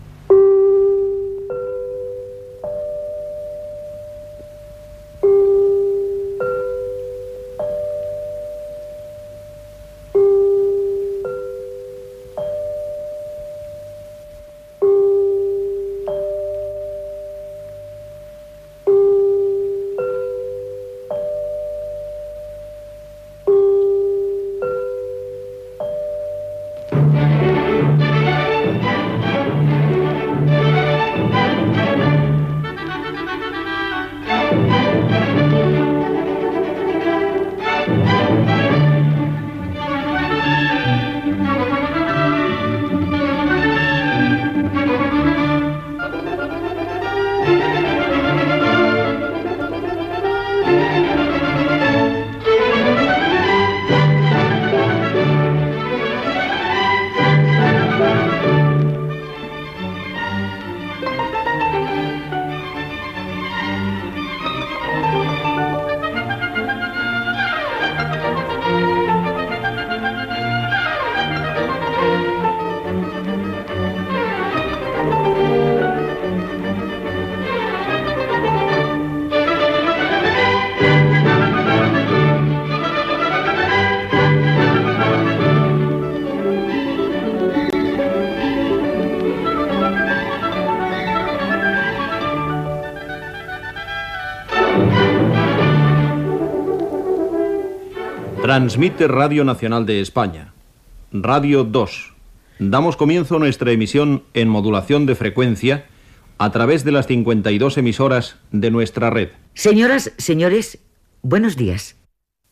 Sintonia inici de la programació